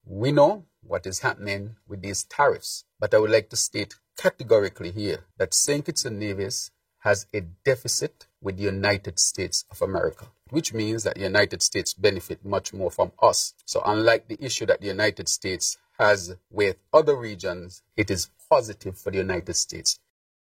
PM Drew, speaking at his roundtable discussion with the media on April 2nd, explain what the information means:
Prime Minister Dr. Terrance Drew.